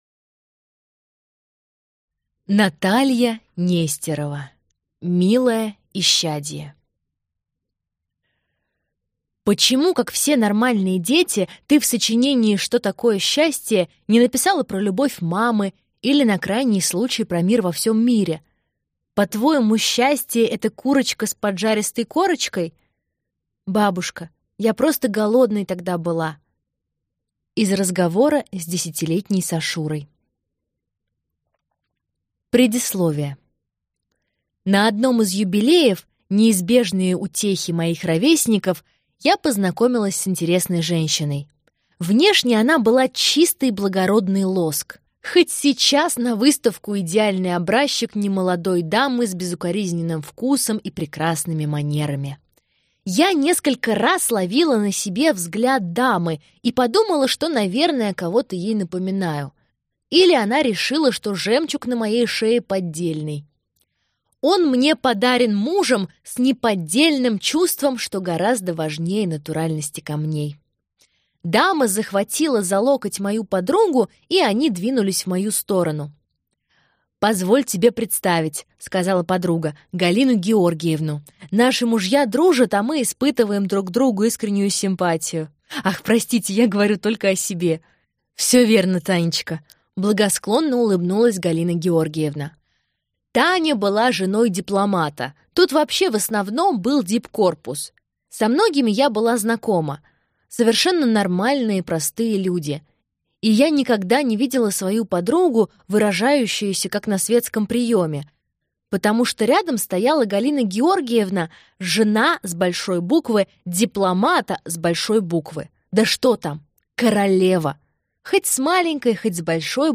Аудиокнига Милое исчадие | Библиотека аудиокниг